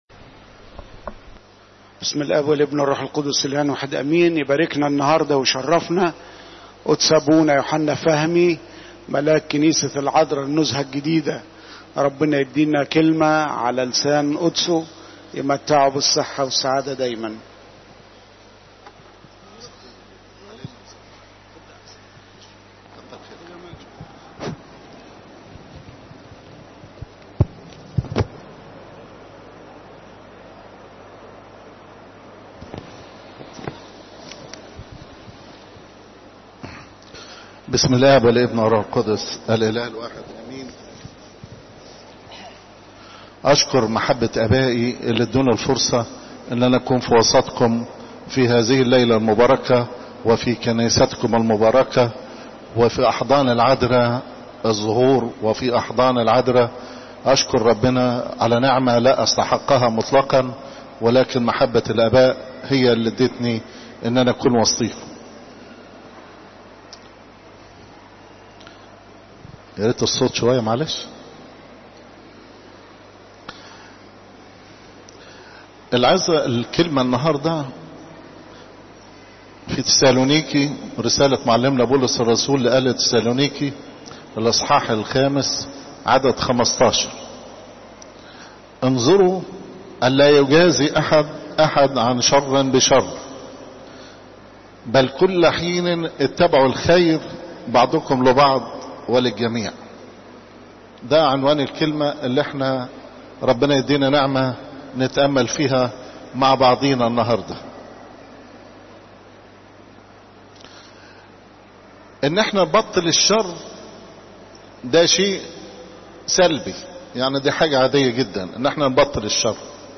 عظات الكنيسة - بطريركية الاقباط الارثوذكس - كنيسة السيدة العذراء مريم بالزيتون - الموقع الرسمي